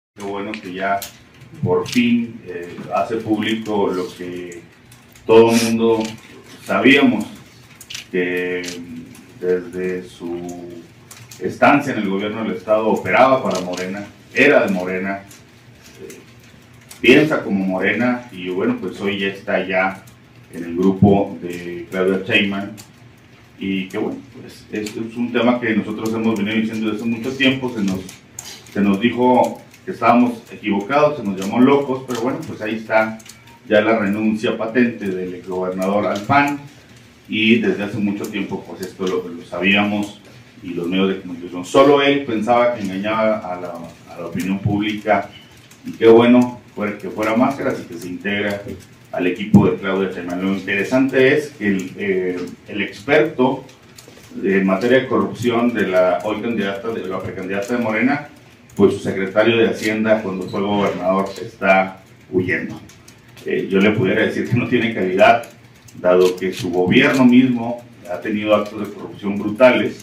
AUDIO: ALFREDO CHÁVEZ, COORDINADOR DEL GRUPO PARLAMENTARIO DEL PARTIDO ACCIÓN NACIONAL (PAN), EN EL CONGRESO DEL ESTADO